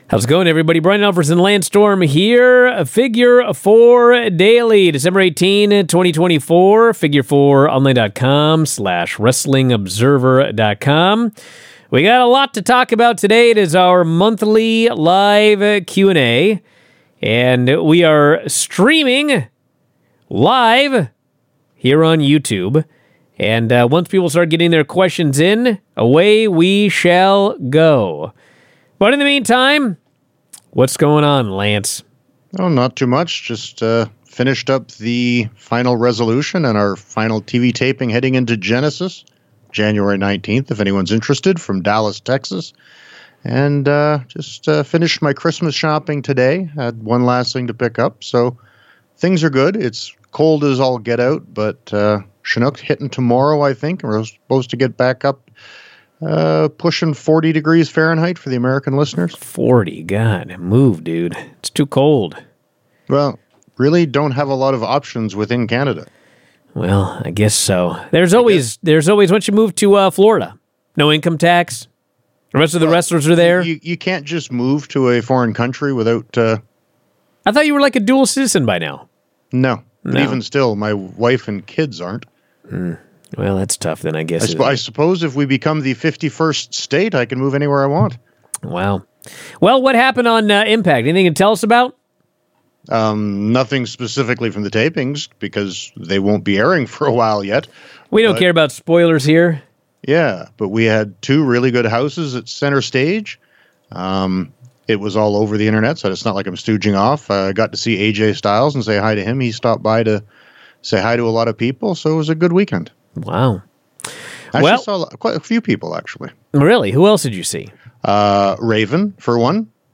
Figure Four Daily: Lance Storm live Q&A